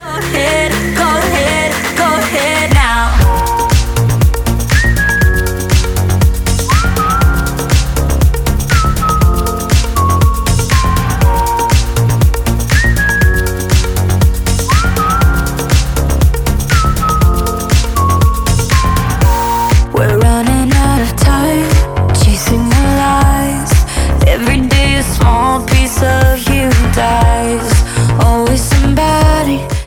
• Pop
is a dance-pop song with an electronic production.